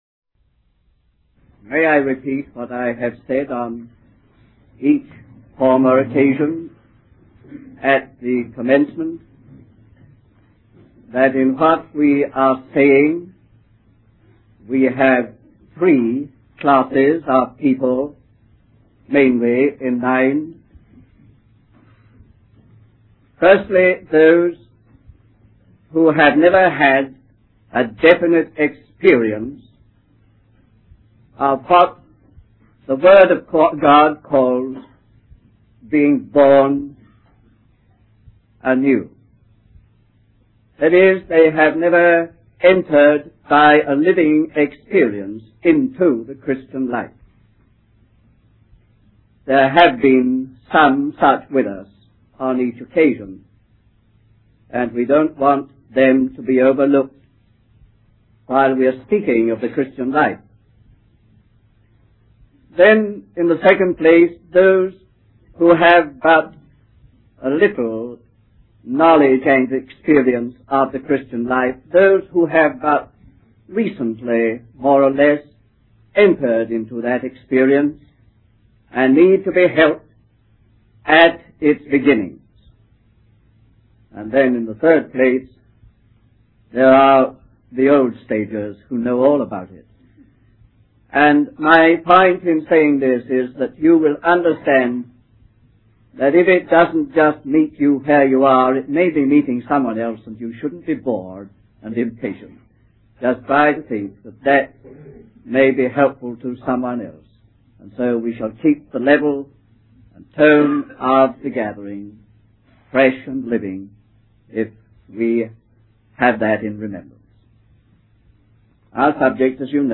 We apologize for the poor quality audio